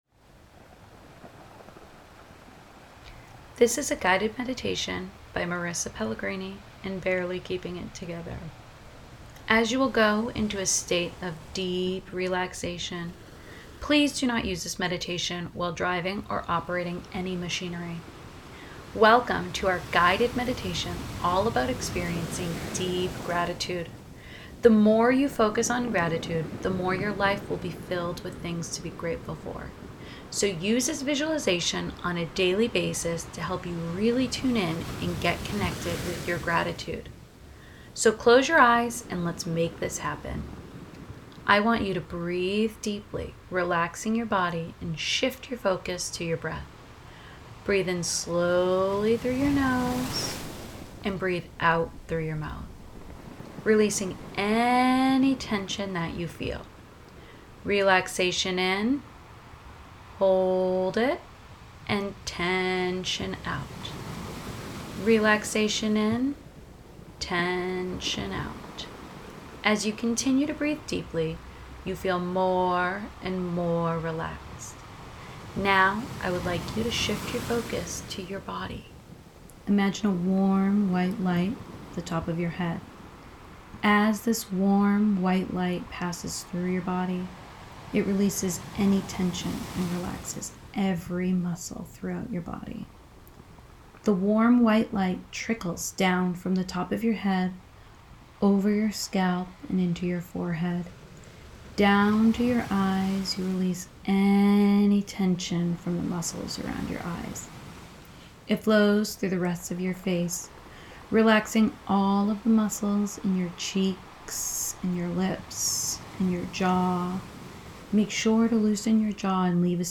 Guided Visualization Meditation Module 3